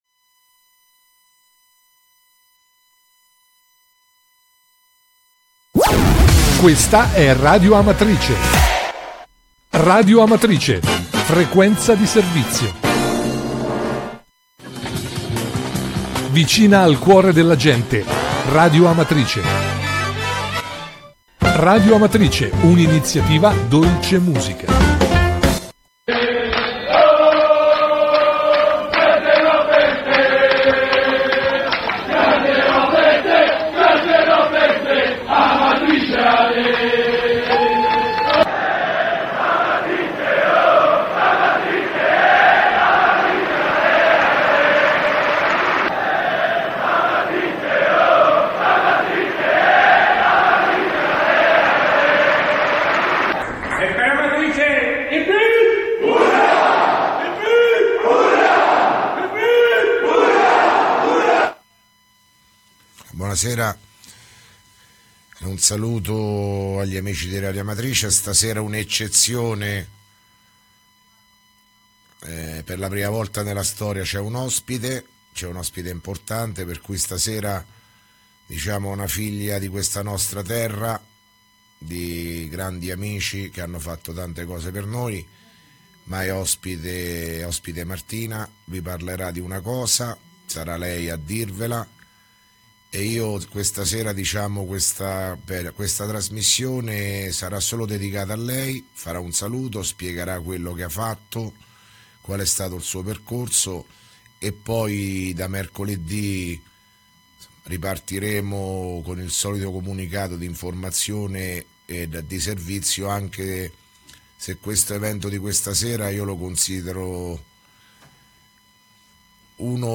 Di seguito il messaggio audio del Sindaco Sergio Pirozzi del 23 OTTOBRE 2017